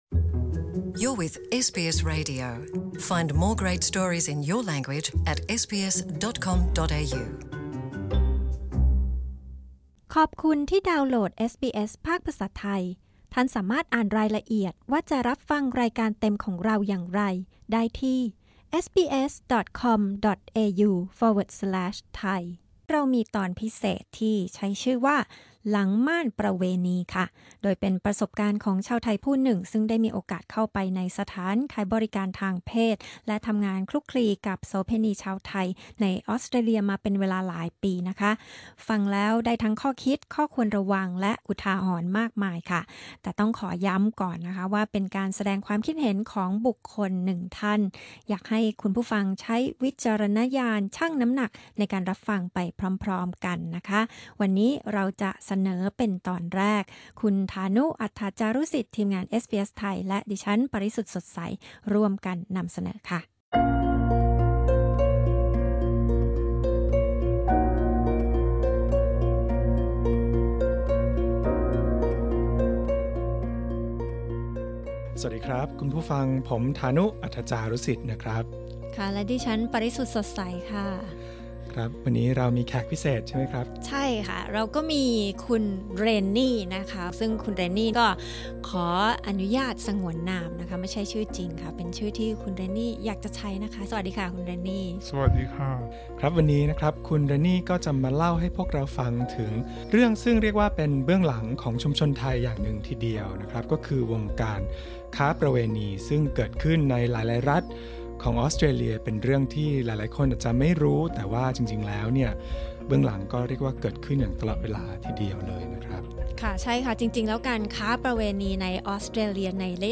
ผู้ให้สัมภาษณ์ได้แสดงความคิดเห็น (opinion) ส่วนตัวของเธอ